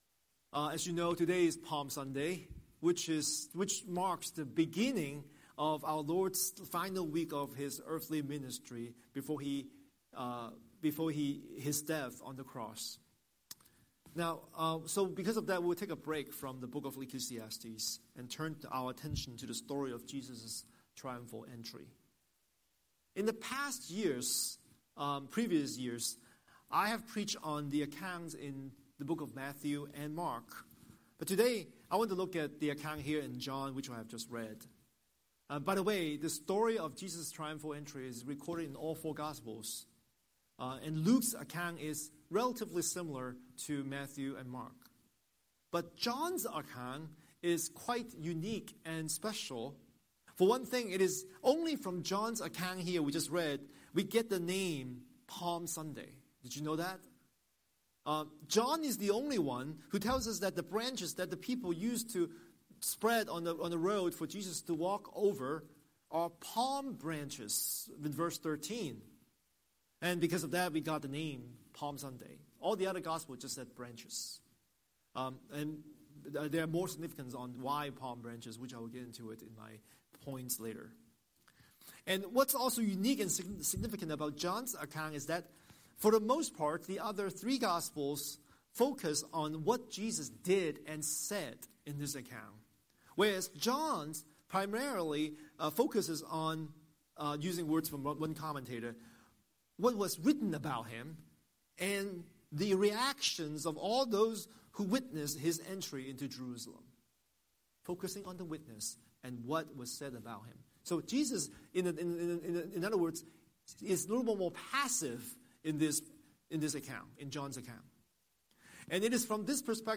Scripture: John 12:12–26 Series: Sunday Sermon